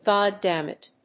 /ˌgɑˈdæmɪt(米国英語), ˌgɑ:ˈdæmɪt(英国英語)/